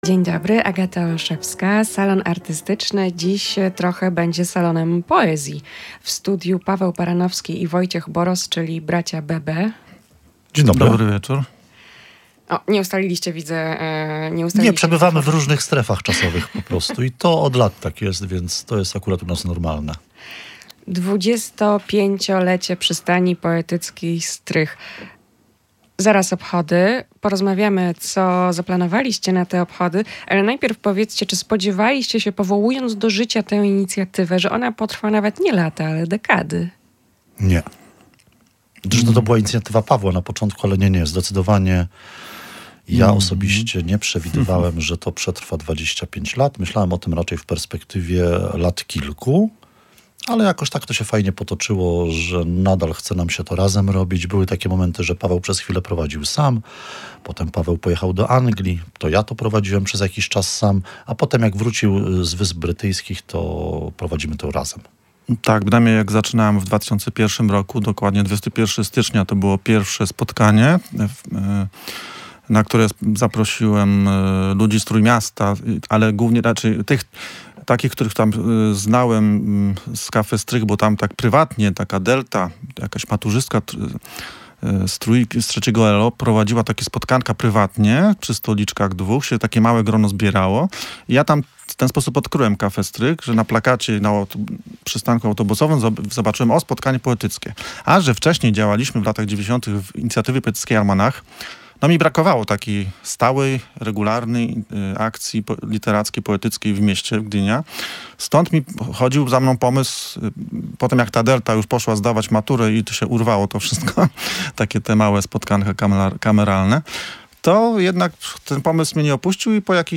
Przystań Poetycka „Strych”, która narodziła się w styczniu 2001 roku, to cykliczne spotkania poetyckie, podczas których można zaprezentować swoje wiersze, wziąć udział w konkursie poezji, czy po prostu poznać nowych ludzi i wymienić doświadczenia. Goszczący w Salonie Artystycznym poeci